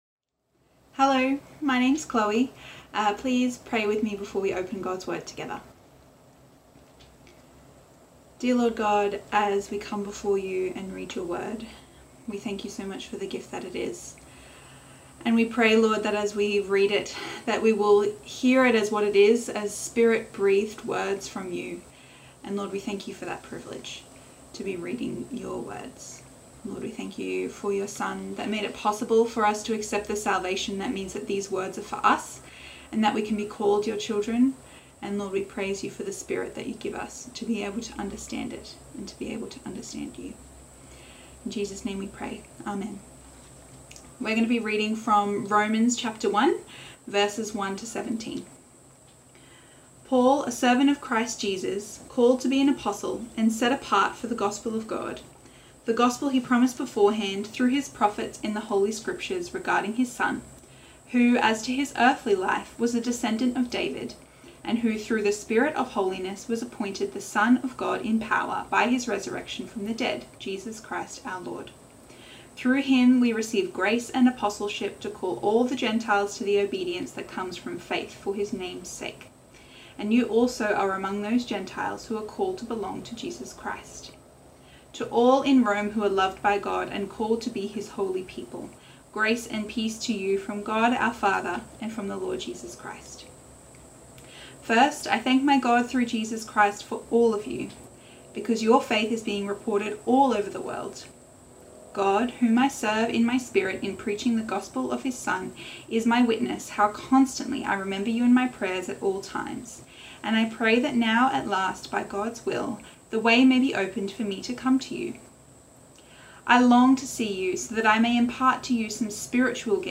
The Power of the Gospel | 17 January 2021 Online Service
Bible Reading & Talk